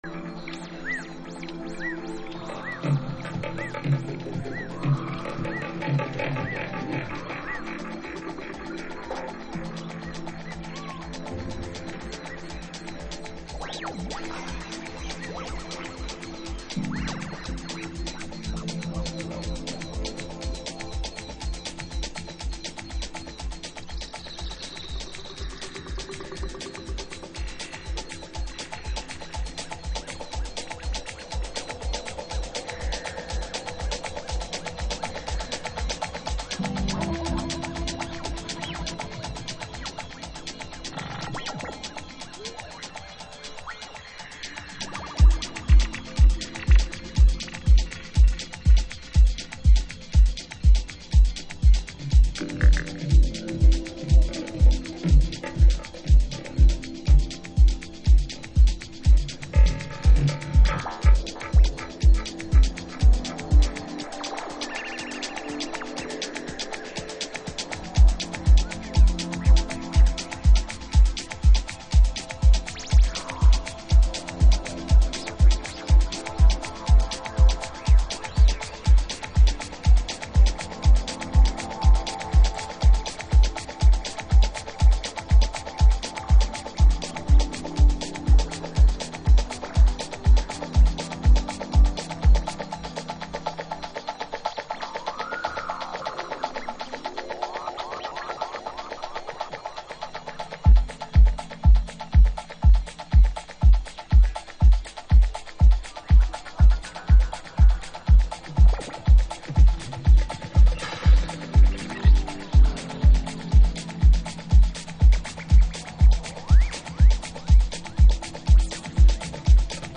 House / Techno
オリジナルより輪郭は少しはっきりしているけど、結局はナードトリップ、森奥深くで思考する